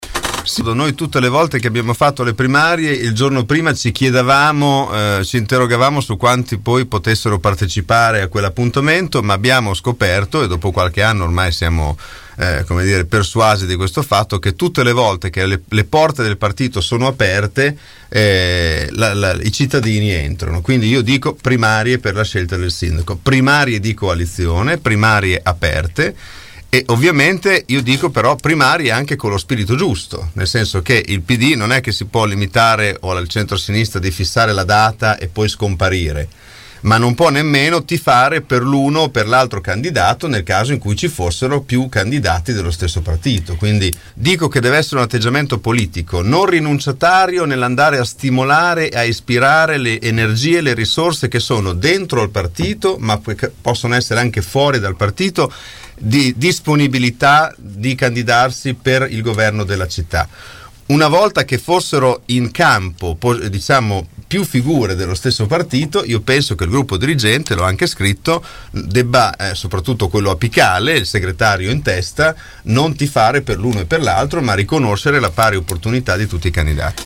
ospiti dei nostri studi.